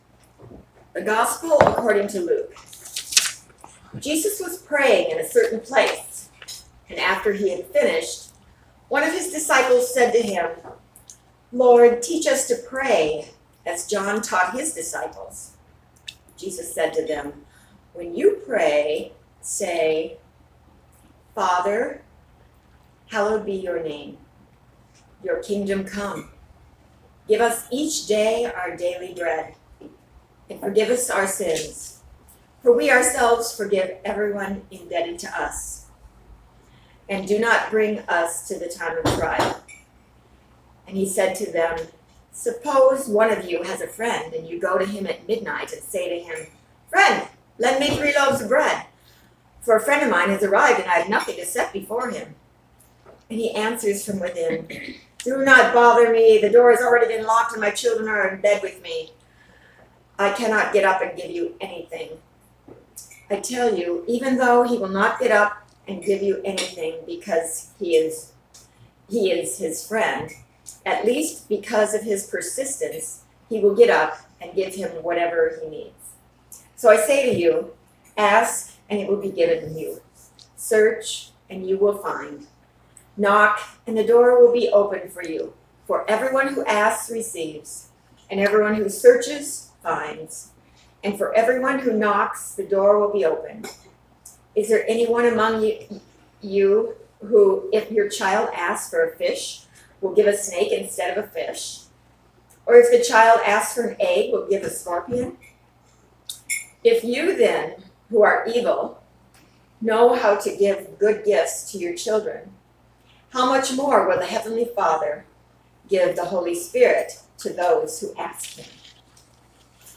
July 28, 2019 Sermon